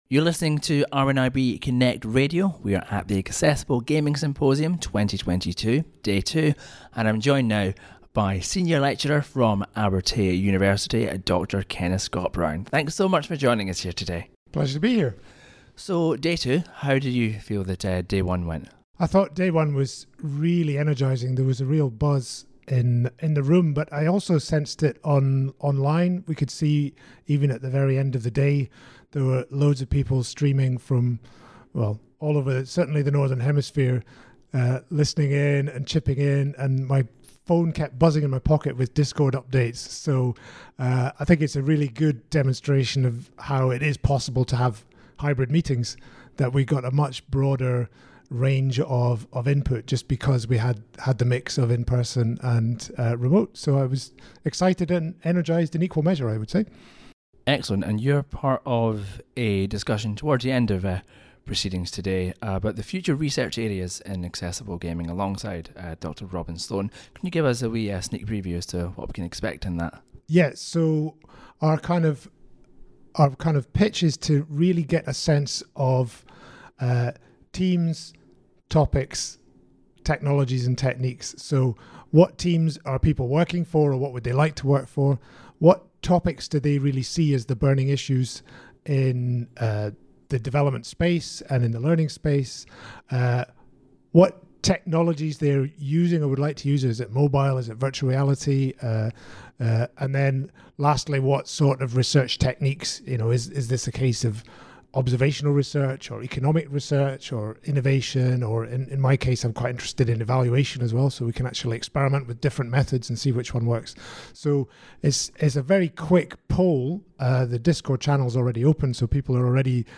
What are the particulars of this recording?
Accessible Gaming Symposium 2022 Interview